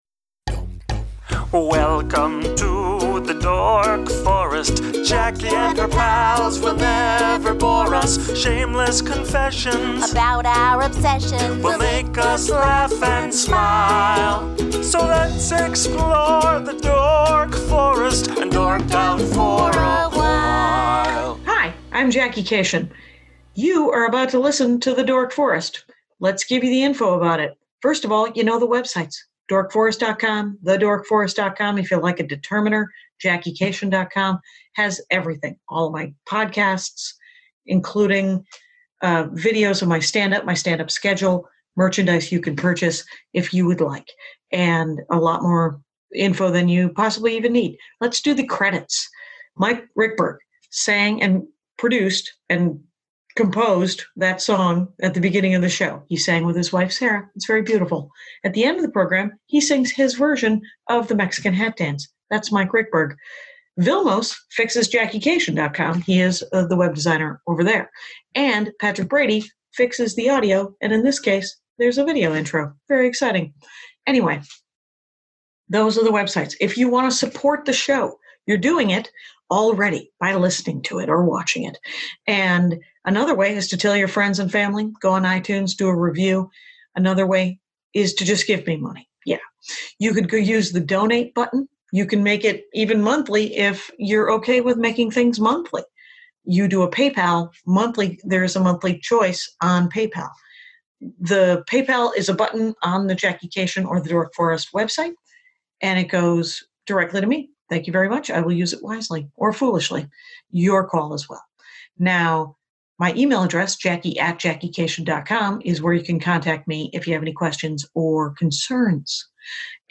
I know even LESS about music in the background of movies. He has a lovely sense of pitch and it’s amazing.